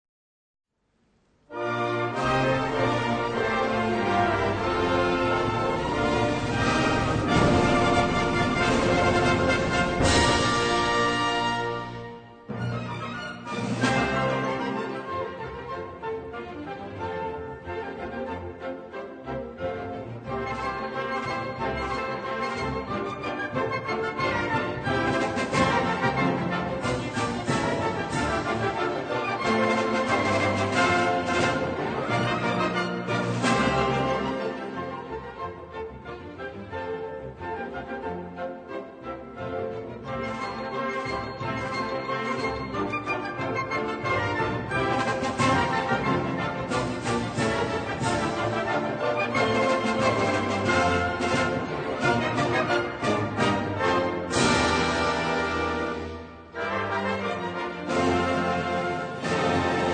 music: Traditional
key: B-major